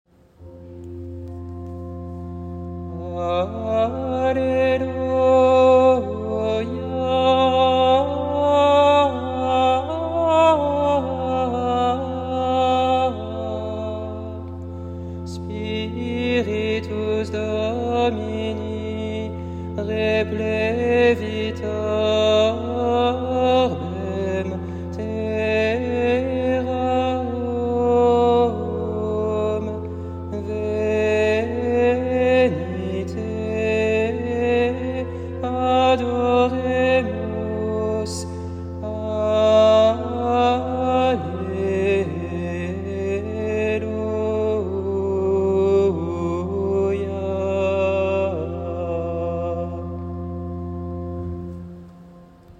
Antienne invitatoire : Alleluia, Spiritus Domini [partition LT]